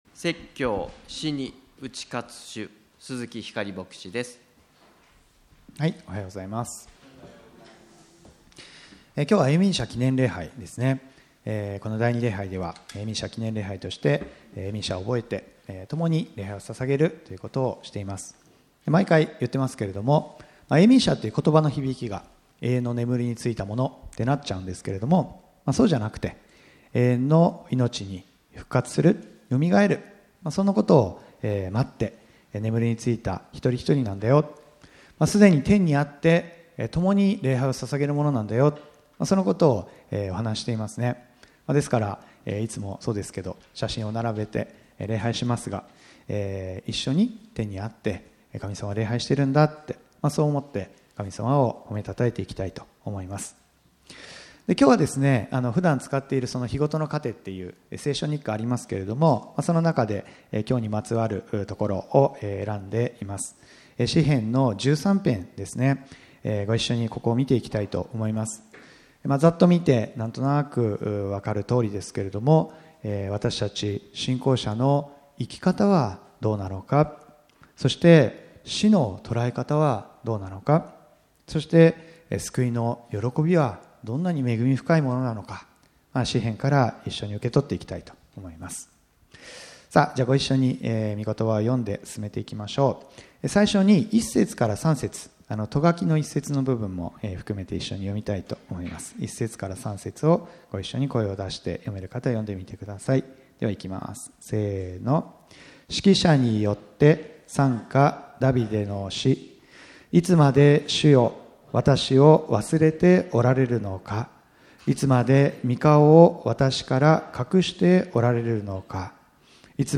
永眠者記念礼拝です。ダビデの祈りから信仰による生き方、死のとらえ方、そして救いの喜びについて思い巡らしましょう。